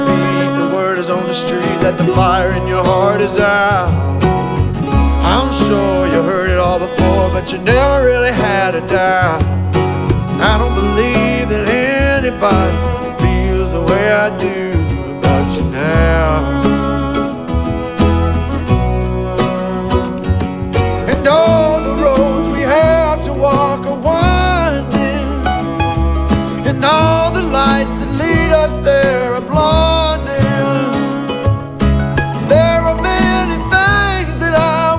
# Bluegrass